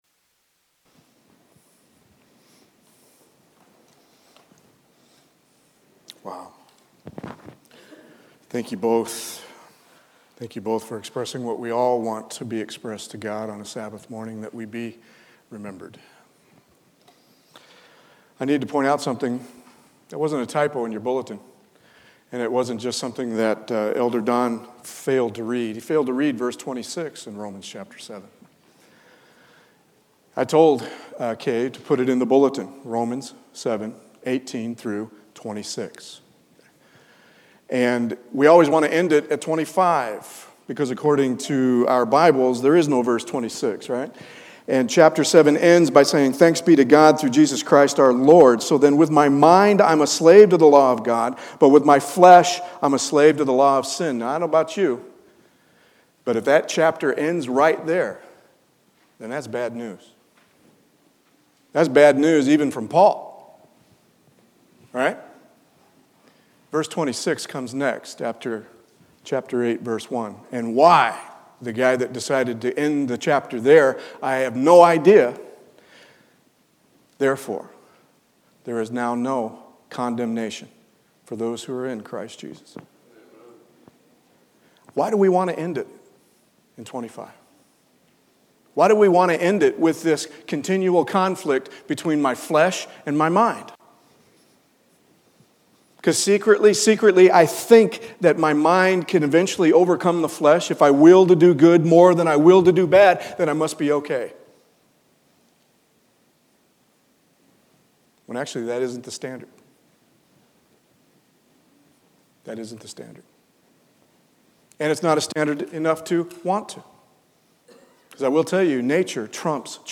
Past Sermons